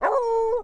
动物 " 马和猎犬
描述：马匹和猎犬聚集在一个混凝土农场院子里。狩猎的主人吹着口哨召唤猎狗。
标签： 猎犬
声道立体声